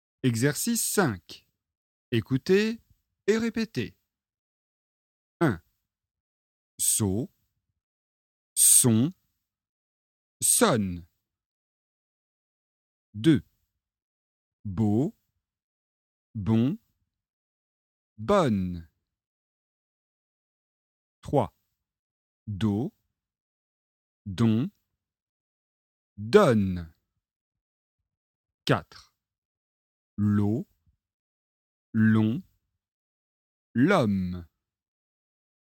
• Leçon de phonétique et exercices de prononciation